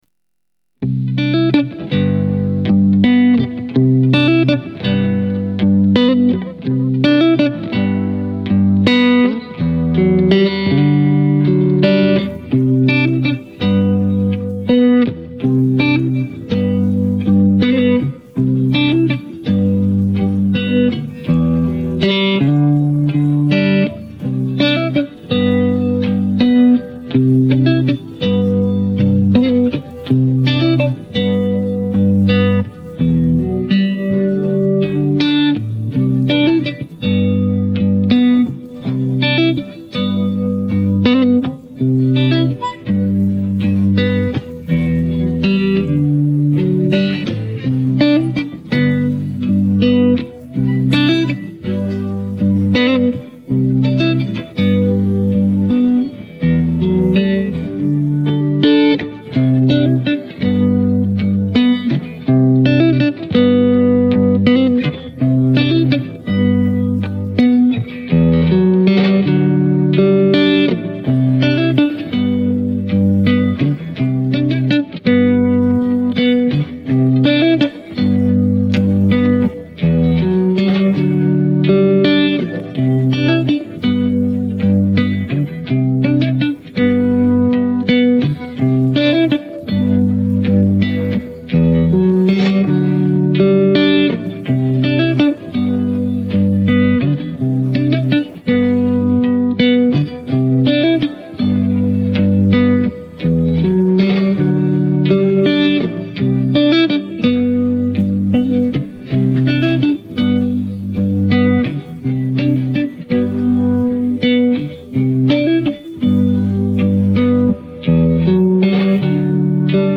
Música de fundo